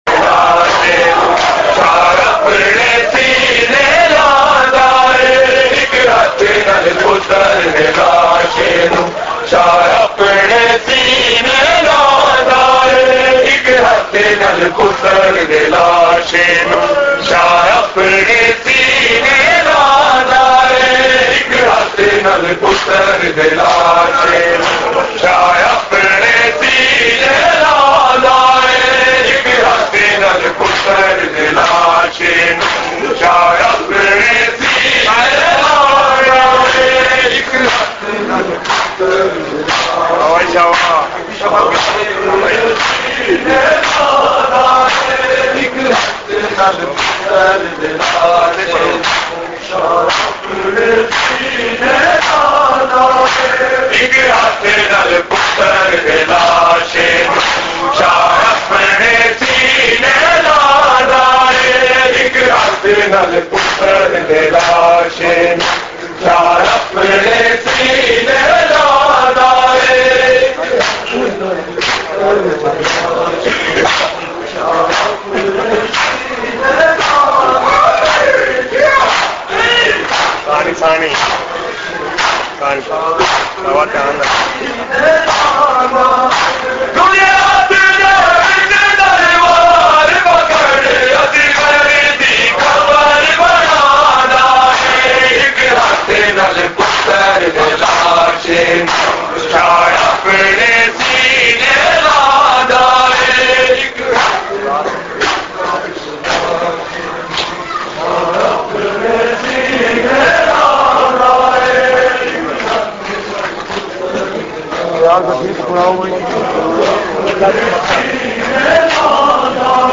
Aik Hath Nal Putar Day Lashay Nu with Matam
Markazi Matmi Dasta, Rawalpindi
Recording Type: Live